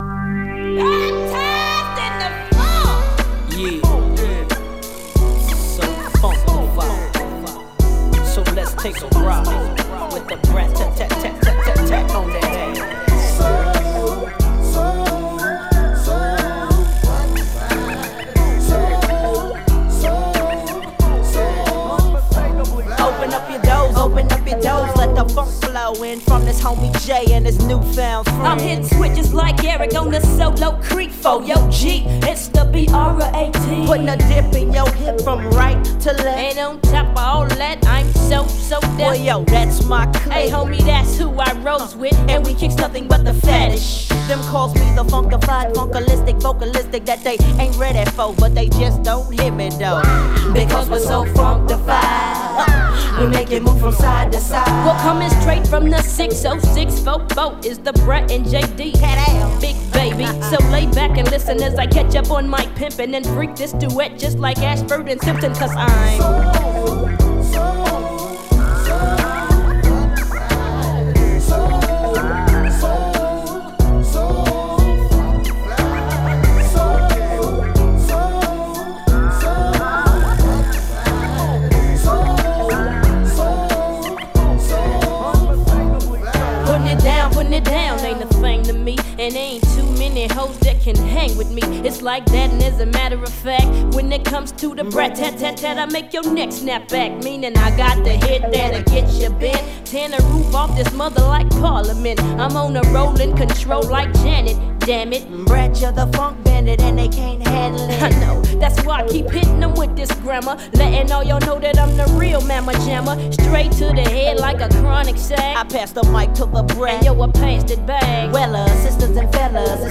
使いのヒップホップ・クラシック！